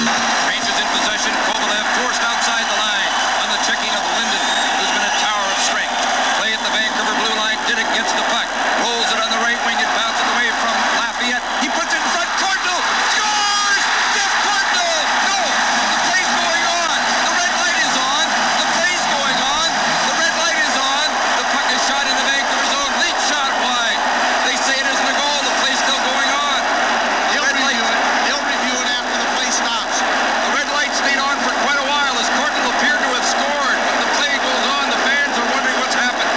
Download Part 1 of the dying moments of Game Six in Vancouver, the 'Best game ever played at the Pacific Coliseum.'